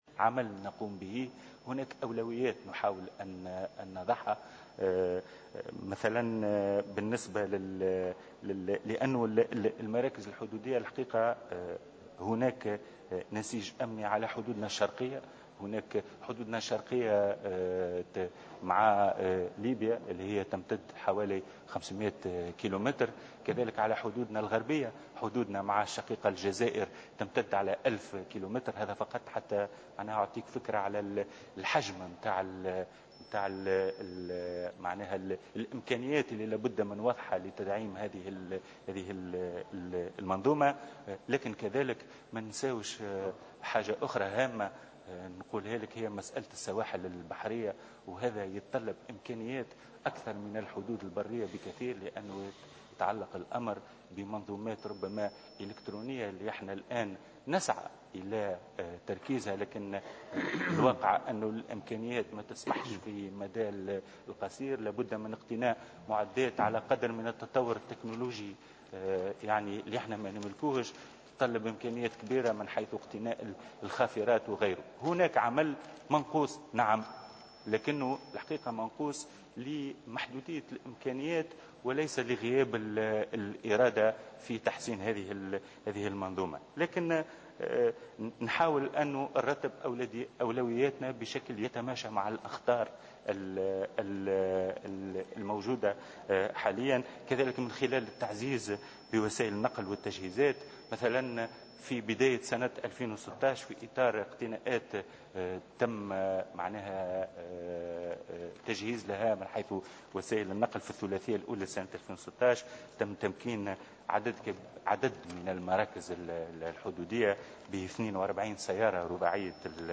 قال وزير الداخلية، الهادي مجدوب اليوم الثلاثاء، خلال جلسة استماع له من قبل نواب مجلس الشعب إنه تمت برمجة 3 مشاريع نموذجية لتهيئة المراكز الحدودية بغار الدماء وحزوة والذهيبة بكلفة جملية ناهزت 22 مليون دينار و ذلك في إطار دعم قدرات المراكز الحدودية على مجابهة مختلف التهديدات.